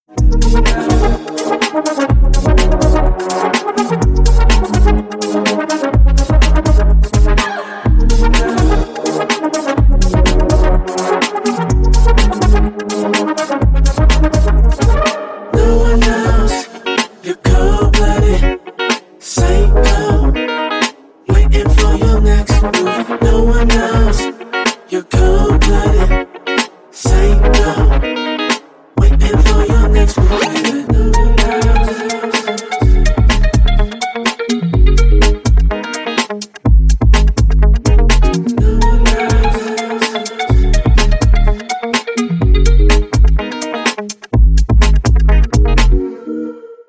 dance
club
low bass
низкий бас
тяжелый бас